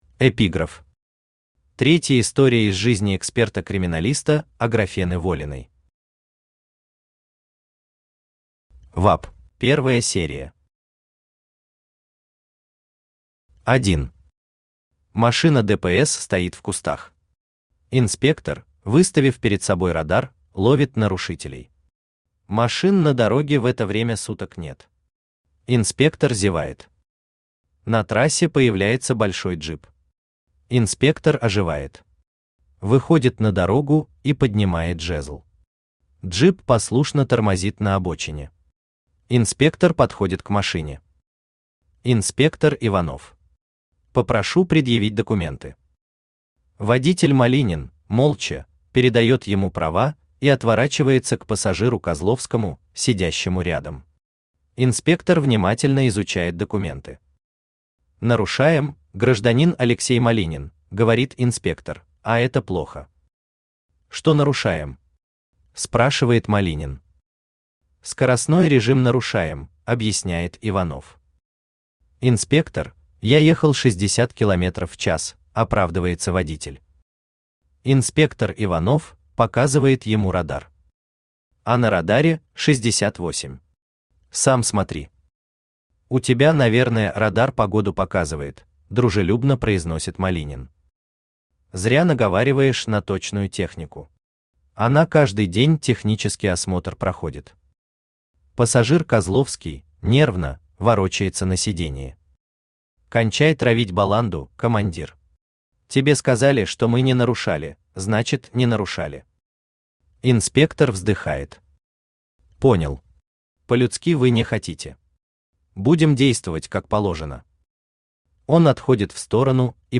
Аудиокнига Чупакабра. Игла | Библиотека аудиокниг
Игла Автор Сергей Алексеевич Глазков Читает аудиокнигу Авточтец ЛитРес.